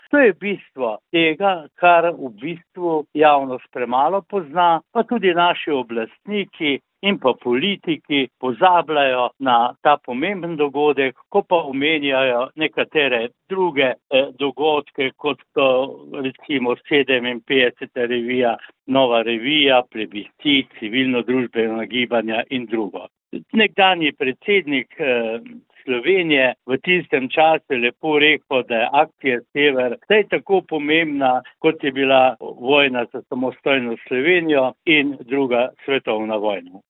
V večnamenski dvorani Lopan v Mislinji je včeraj potekal spominski dan Združenja Sever.